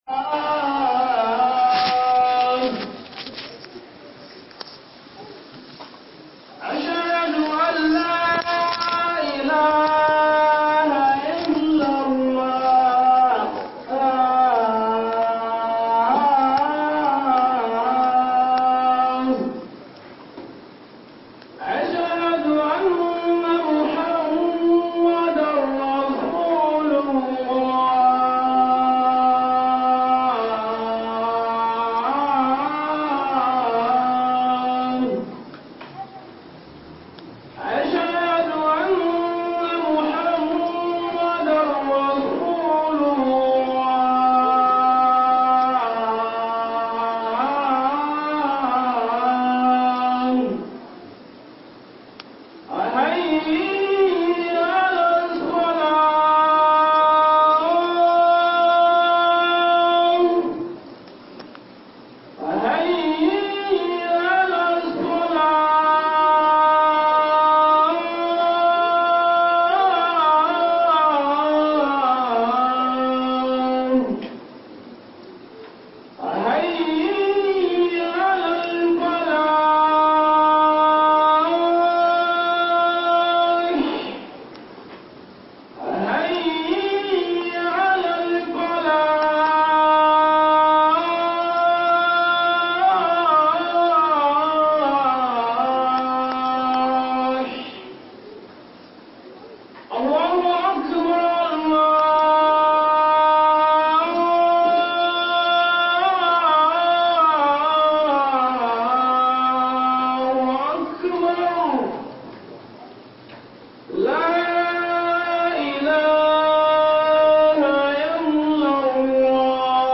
Hudubar Jumaa Abin Hawa